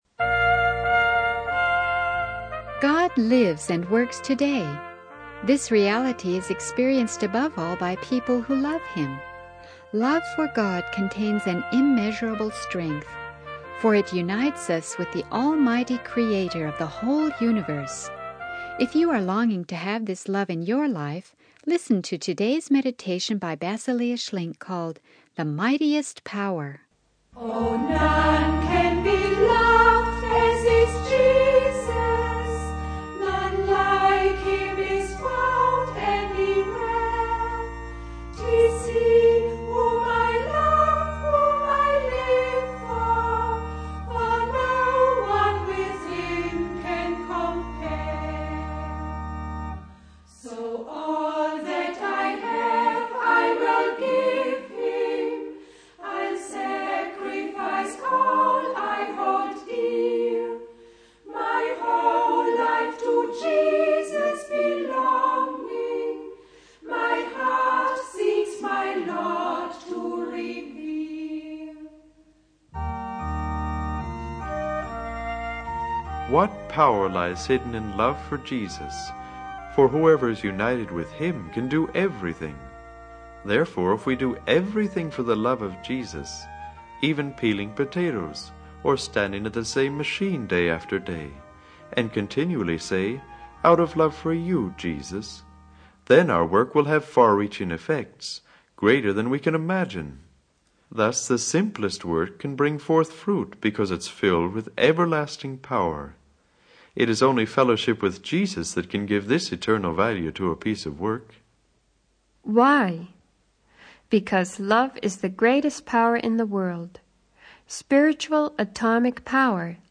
The sermon emphasizes that love for Jesus is the mightiest power, enabling us to find purpose and fruitfulness in our lives through union with Him.